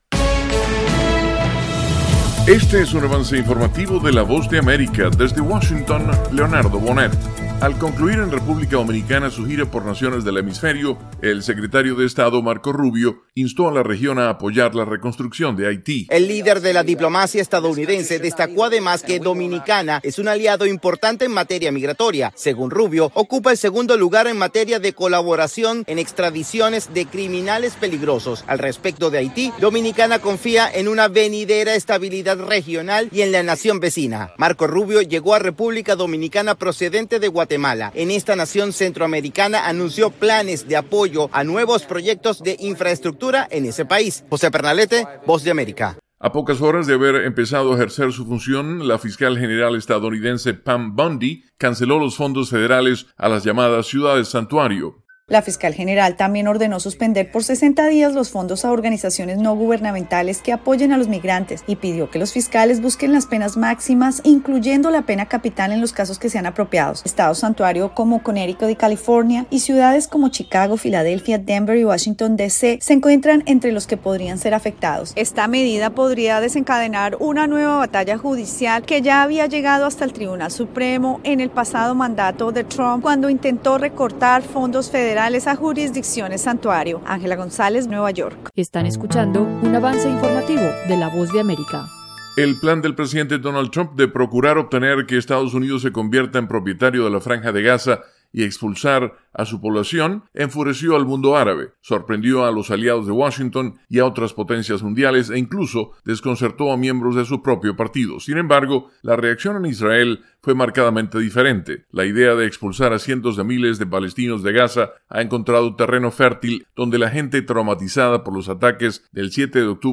Avance Informativo